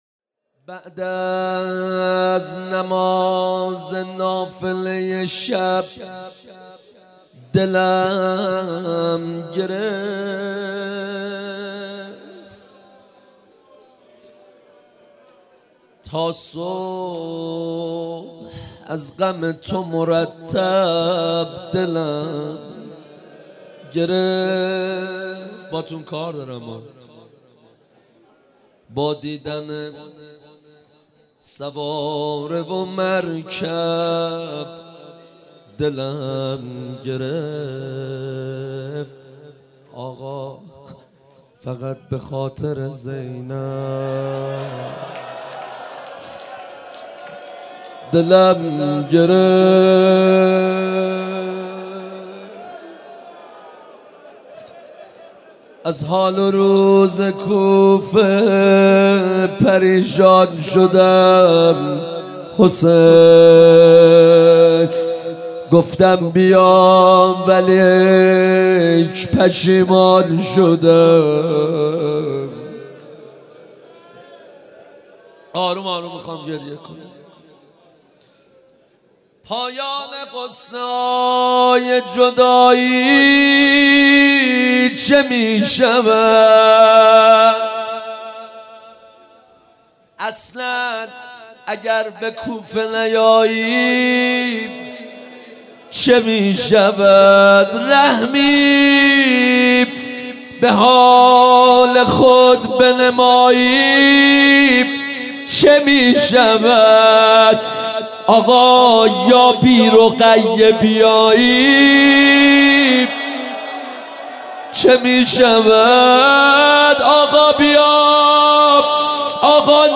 شب اول محرم الحرام 1394 | هیات مکتب الحسین اصفهان
بعد از نماز نافله ی شب دلم گرفت | روضه | حضرت مسلم بن عقیل علیه السلام